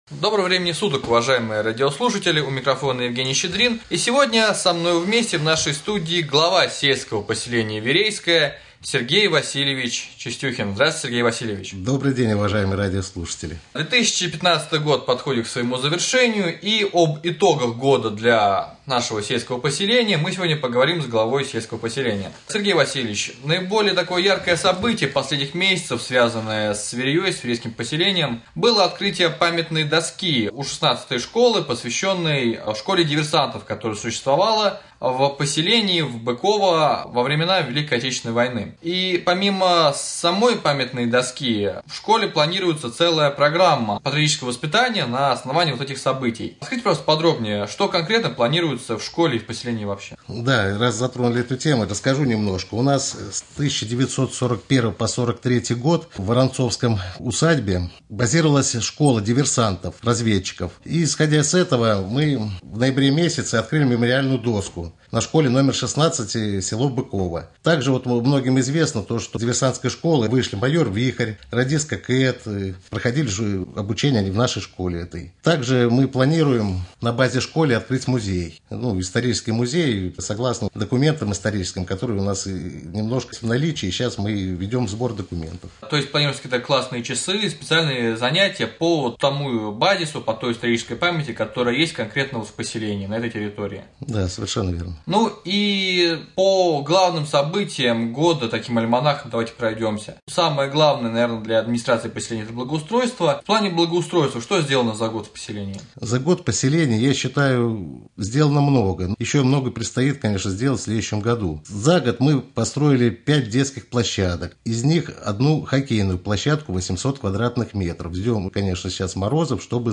Глава сельского поселения Верейское на Раменском радио.
В Гостях у Раменского радио побывал глава Верейского поселения Сергей Васильевич Чистюхин. Разговор шел об итогах уходящего 2015 года, а так же о планах на наступающий 2016 год. Благоустройство, культура, бюджет – вот основные темы для главы поселения.